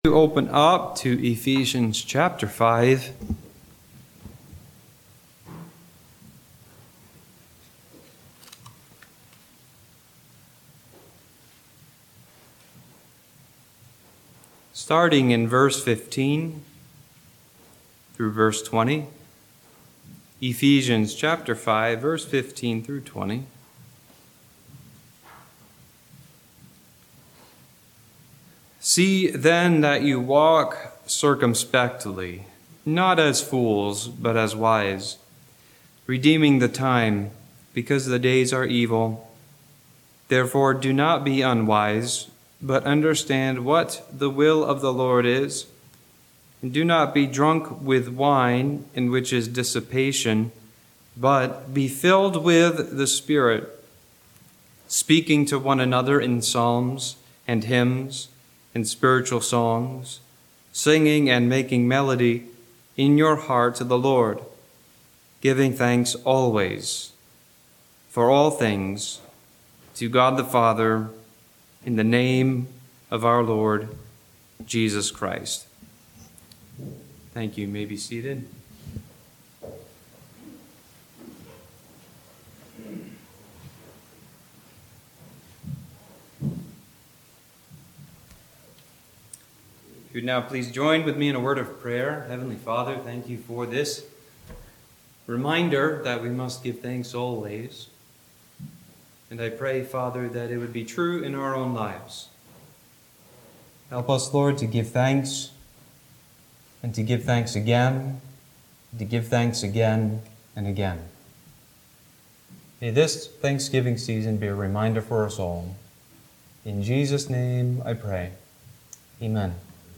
Service Type: Special Occasion
Thanksgiving-Eve.mp3